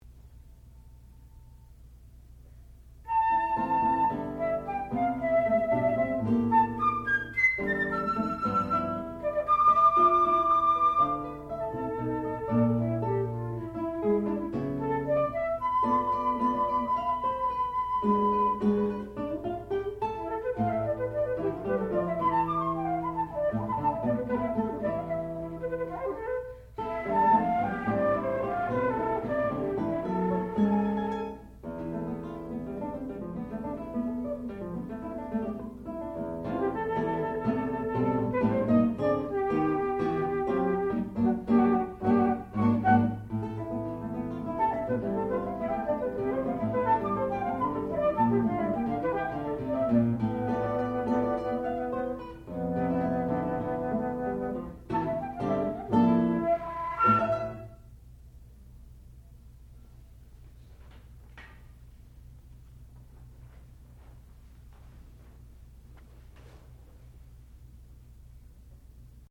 Four Excursions for Guitar and Flute (1971)
classical music
Advanced Recital